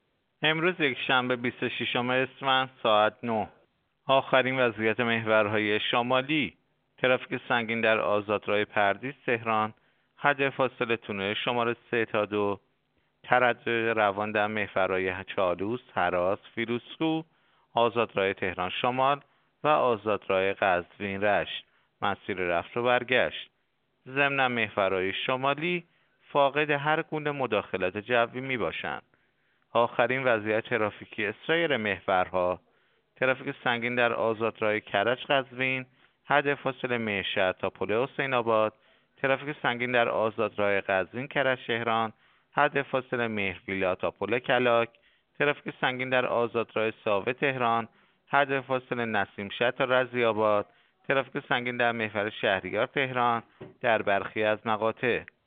گزارش رادیو اینترنتی از آخرین وضعیت ترافیکی جاده‌ها ساعت ۹ بیست و ششم اسفند؛